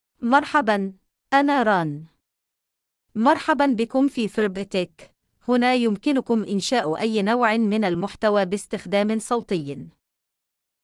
Rana — Female Arabic (Iraq) AI Voice | TTS, Voice Cloning & Video | Verbatik AI
Rana is a female AI voice for Arabic (Iraq).
Voice sample
Listen to Rana's female Arabic voice.
Rana delivers clear pronunciation with authentic Iraq Arabic intonation, making your content sound professionally produced.